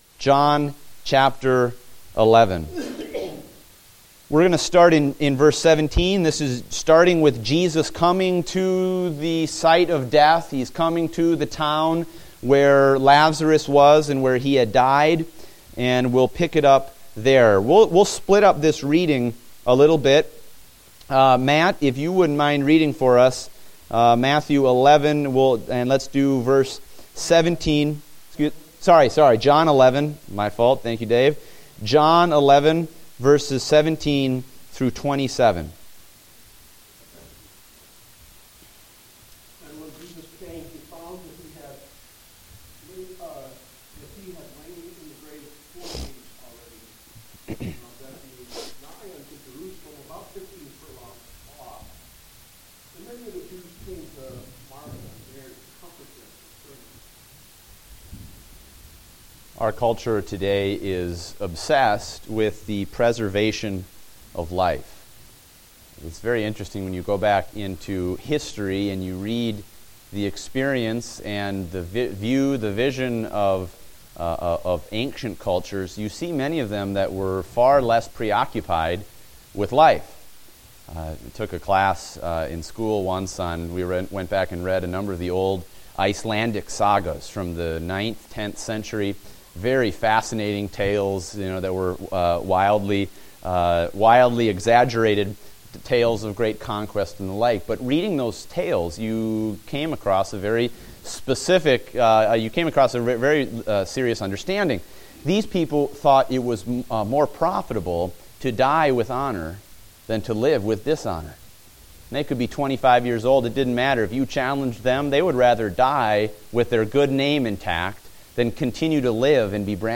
Date: February 7, 2016 (Adult Sunday School)